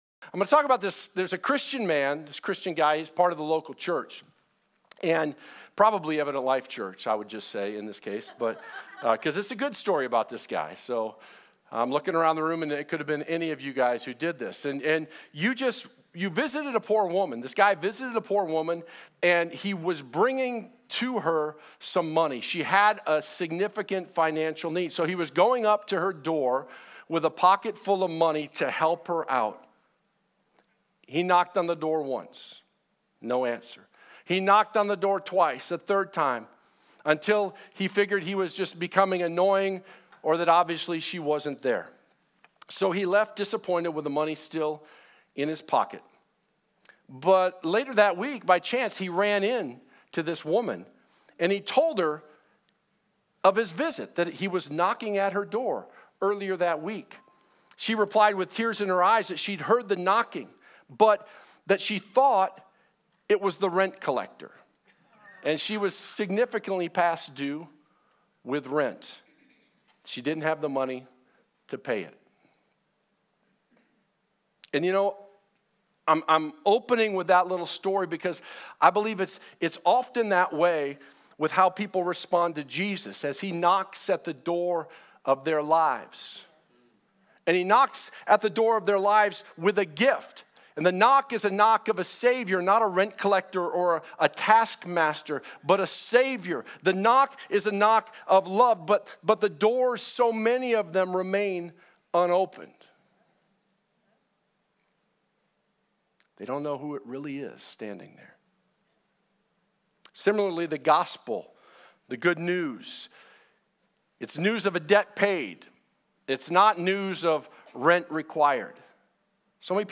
Sermon Archive (2016-2022) - Evident Life Church | Gospel-Centered, Spirit Filled Church in Gilbert, AZ